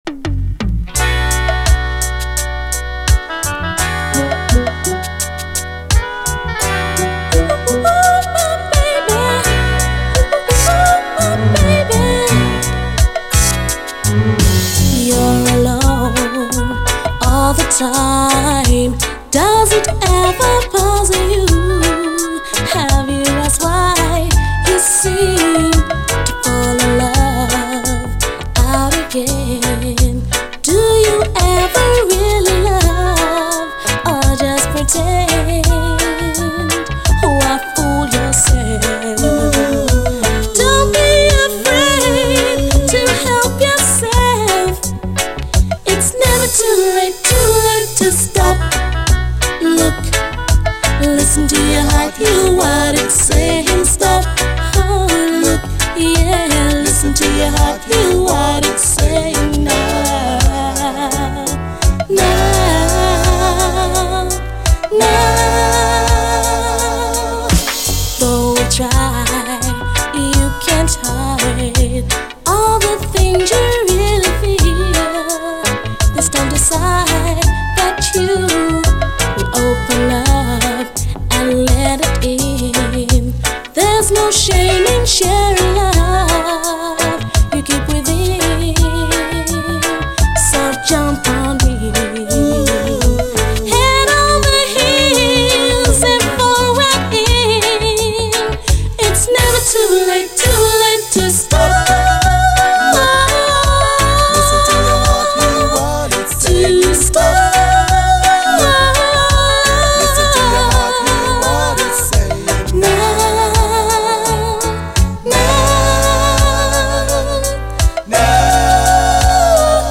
REGGAE
（開始８秒のところのプチノイズっぽい音は全てのコピーで入る音のようです。）